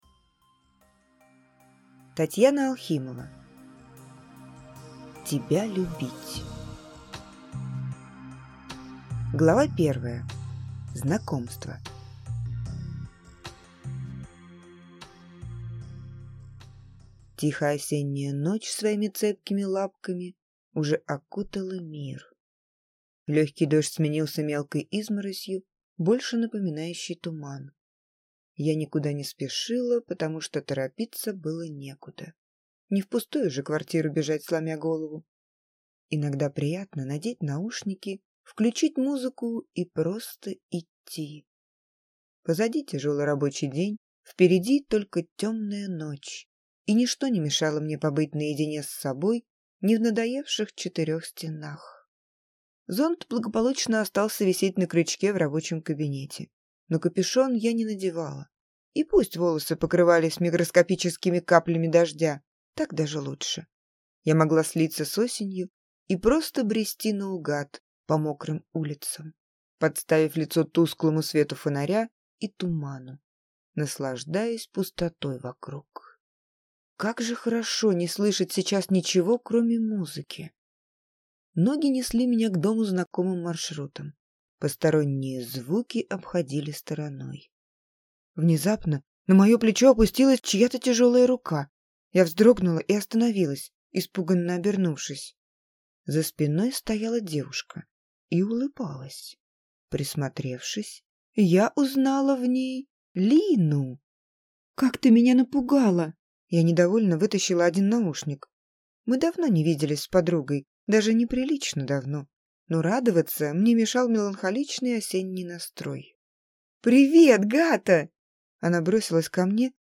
Аудиокнига Тебя любить | Библиотека аудиокниг